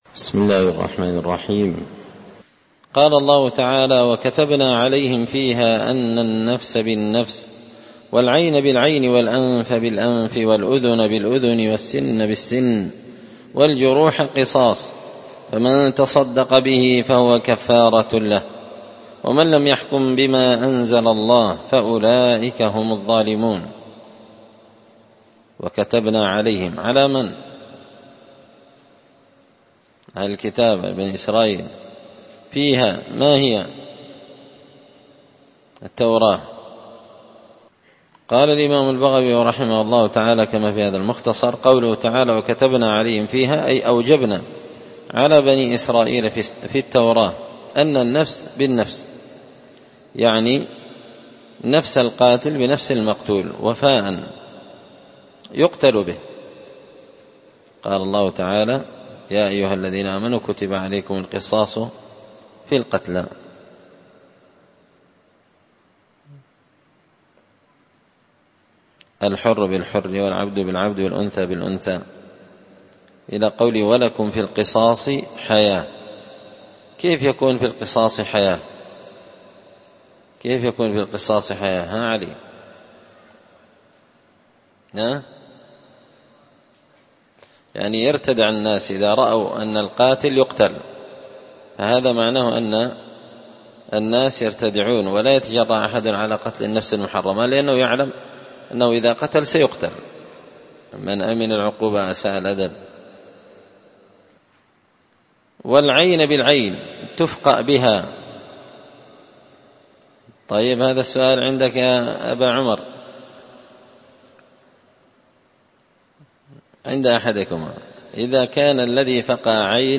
ألقيت هذه الدروس في 📓 # دار _الحديث_ السلفية _بقشن_ بالمهرة_ اليمن 🔴مسجد الفرقان